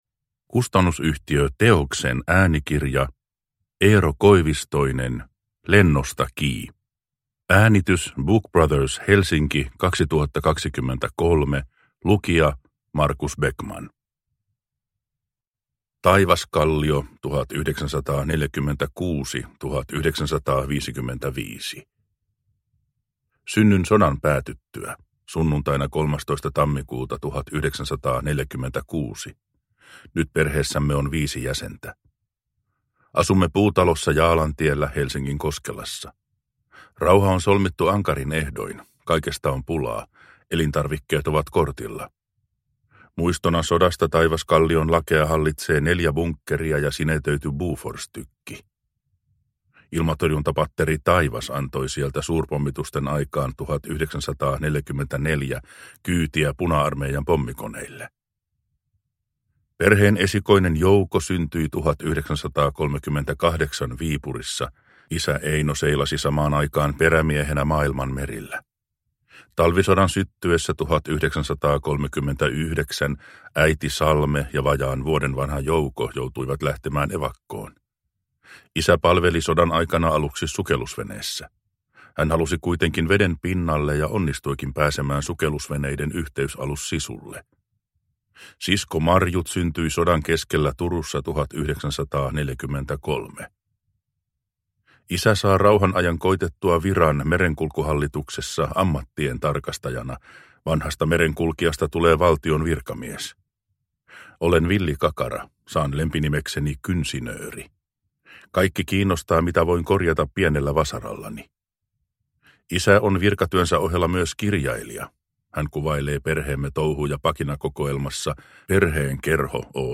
Lennosta kii! – Ljudbok